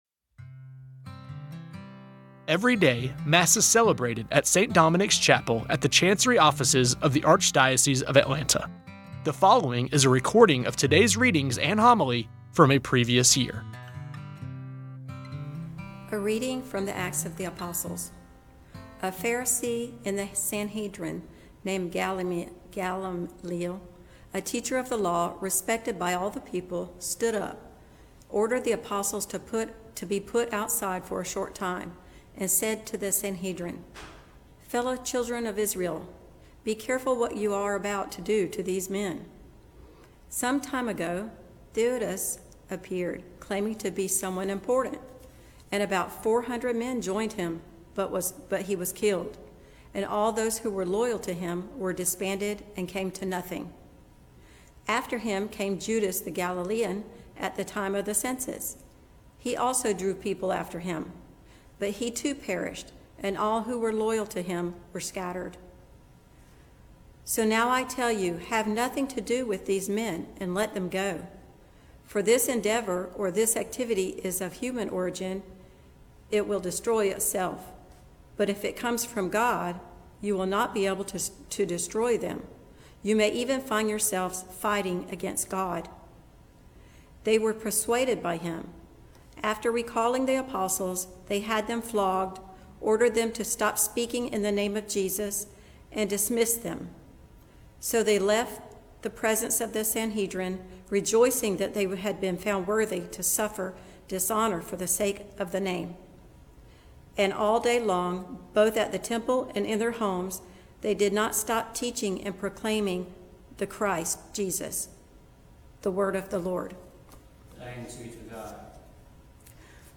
Every day, Mass is celebrated at St. Dominic’s Chapel at the Chancery Offices of the Archdiocese of Atlanta. The following is a recording of today’s readings and homily from a previous year. You may recognize voices proclaiming the readings and homilies as employees, former employees, or friends of the Archdiocese.